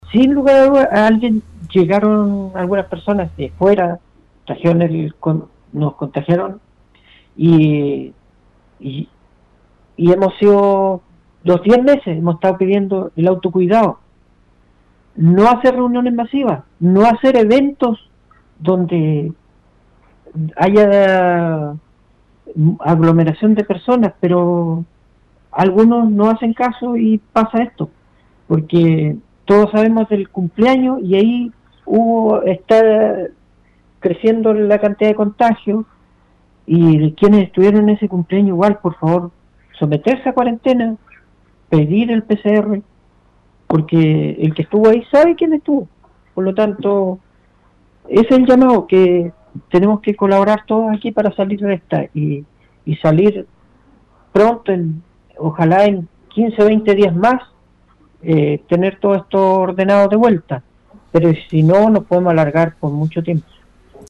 Entre las explicaciones para este aumento sostenido de personas afectadas por el coronavirus, el alcalde Ricardo Soto expresó que se registró ingreso de personas de fuera de la comuna, pero también hubo causales internas.